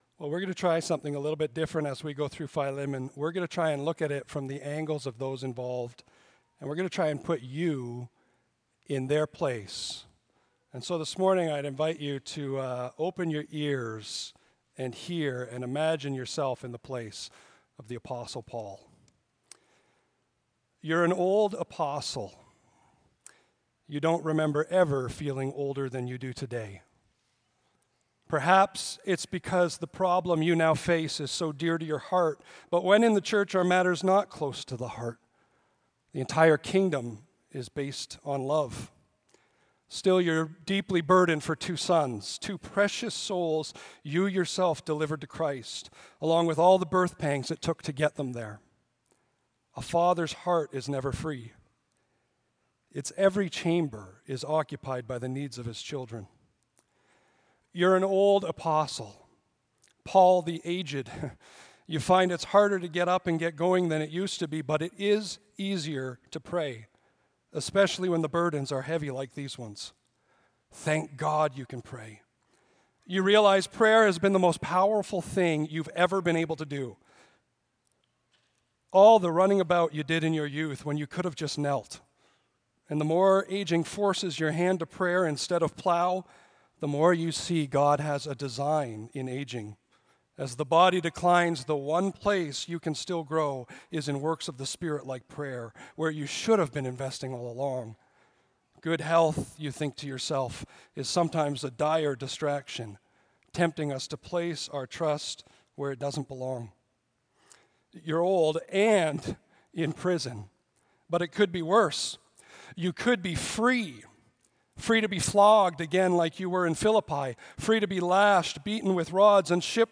Audio Sermon Library Lessons from a Runaway Slave: According to Paul.